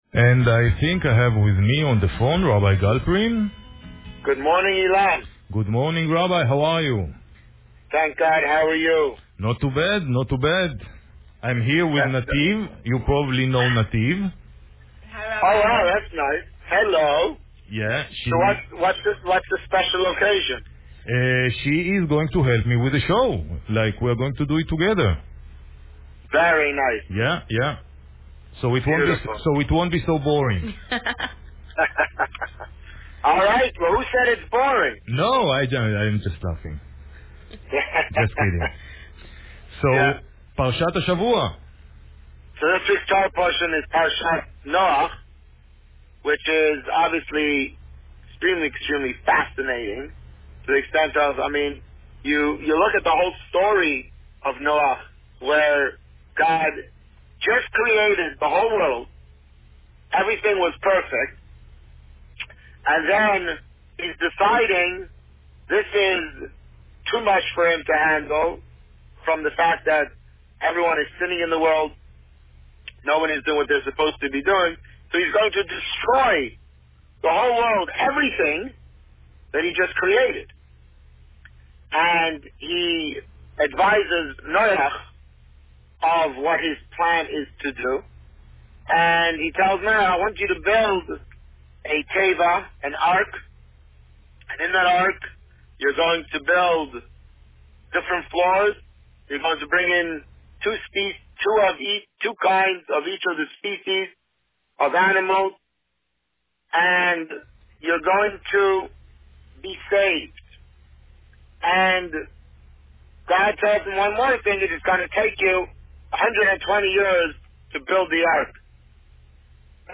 This week, the Rabbi spoke about Parsha Noach. Listen to the interview here.